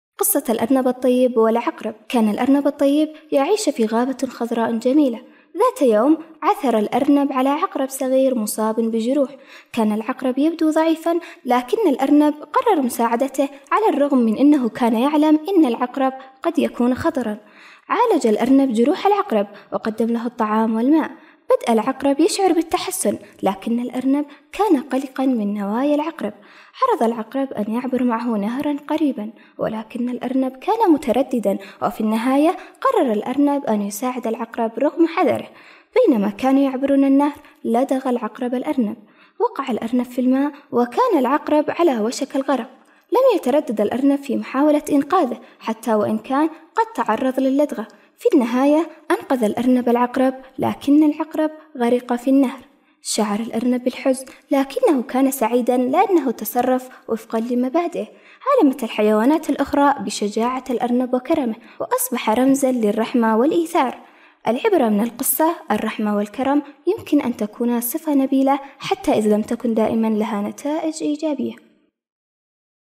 قصة قصيرة